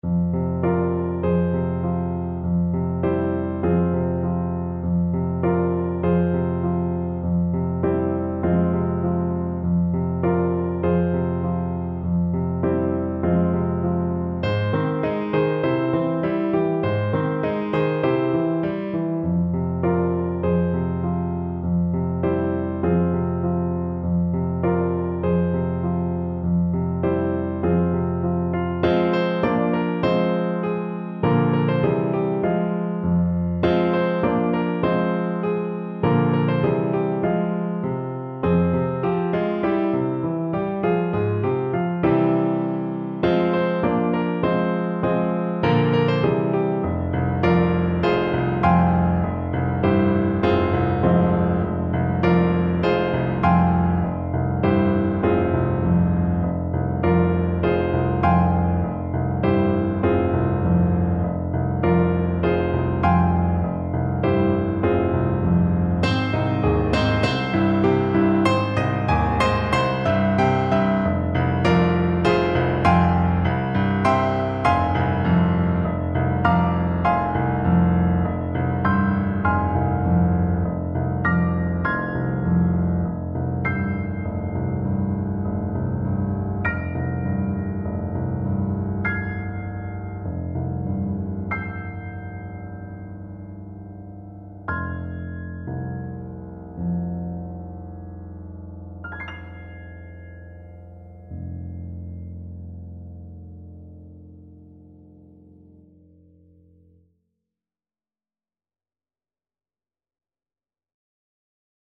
Play (or use space bar on your keyboard) Pause Music Playalong - Piano Accompaniment Playalong Band Accompaniment not yet available transpose reset tempo print settings full screen
F minor (Sounding Pitch) C minor (French Horn in F) (View more F minor Music for French Horn )
Moderato